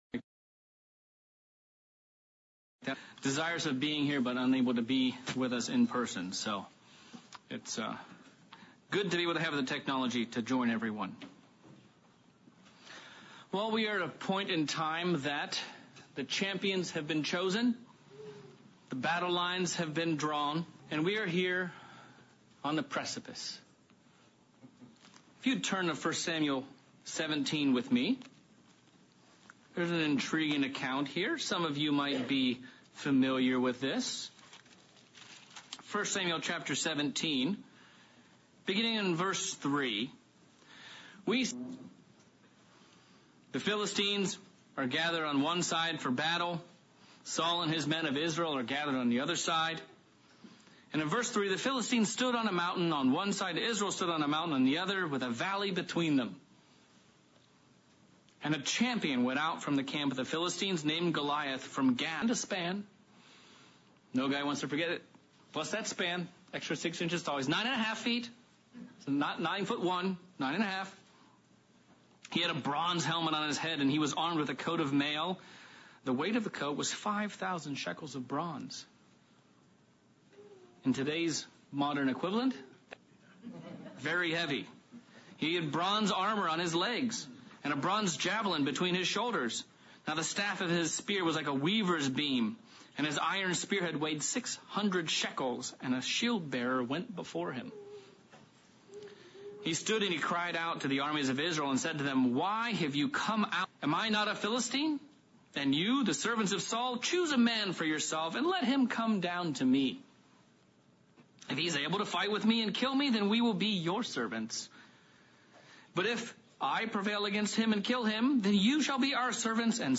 Sermon looking at the battle between David and Goliath and considering which approach should we take. Should we stand at the edge of the hill hurling insults at each other or should we instead be ambassadors for reconciliation.